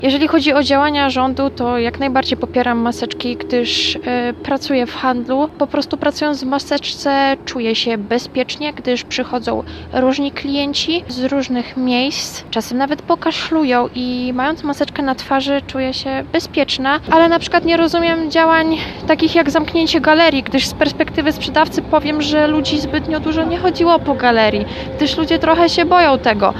SONDA-3-obostrzenia-maseczki.mp3